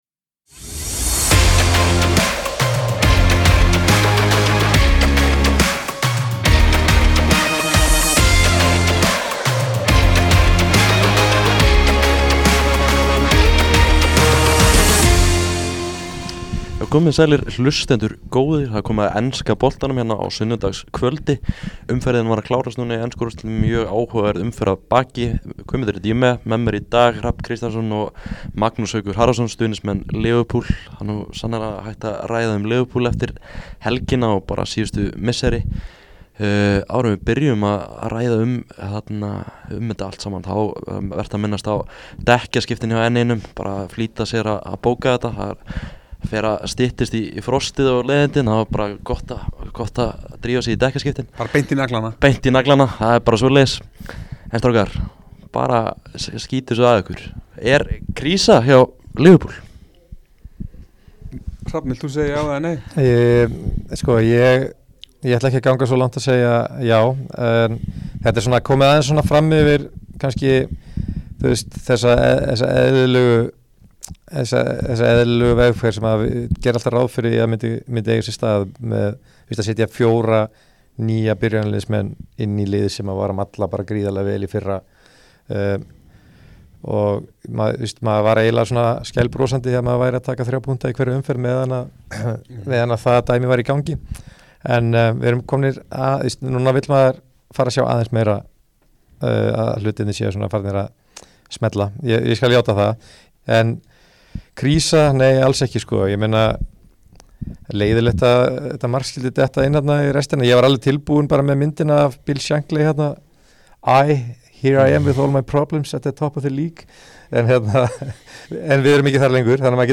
í Pepsi Max stúdíóinu